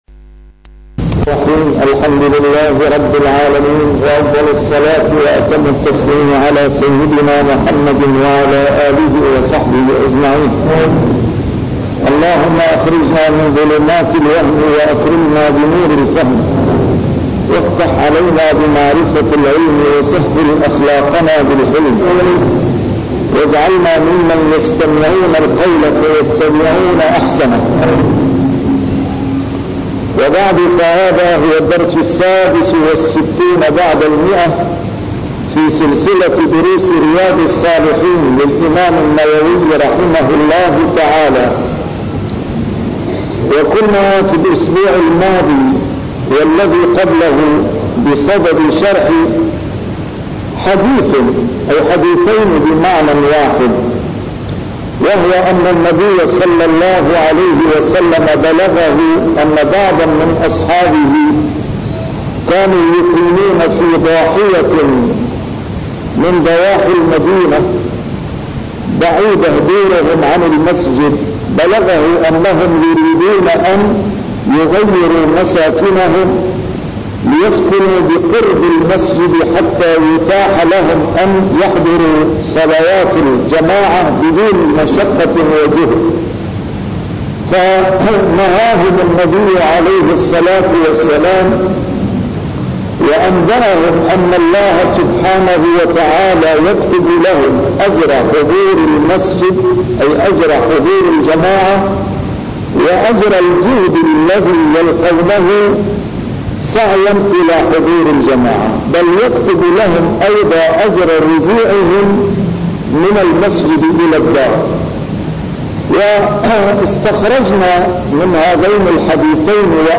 شرح كتاب رياض الصالحين - A MARTYR SCHOLAR: IMAM MUHAMMAD SAEED RAMADAN AL-BOUTI - الدروس العلمية - علوم الحديث الشريف - 166- شرح رياض الصالحين: كثرة طرق الخير